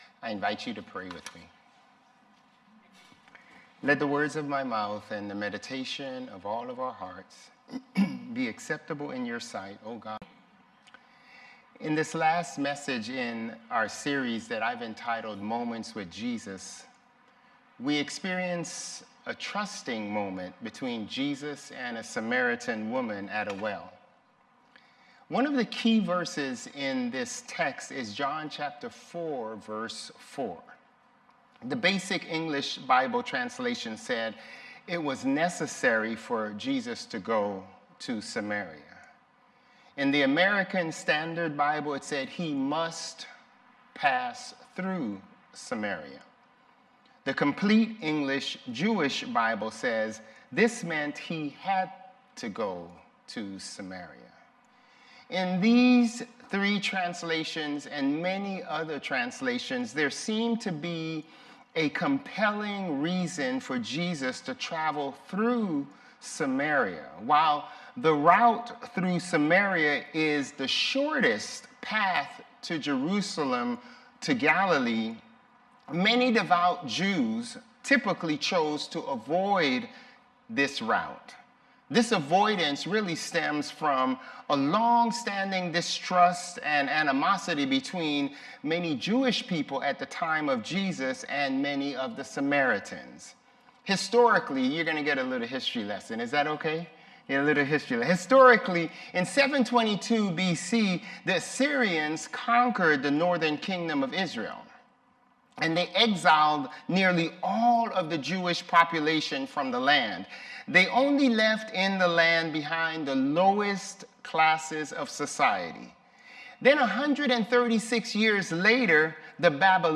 Sermons | Bethel Lutheran Church
August 3 Worship